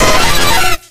infinitefusion-e18/Audio/SE/Cries/ZAPDOS.ogg at a50151c4af7b086115dea36392b4bdbb65a07231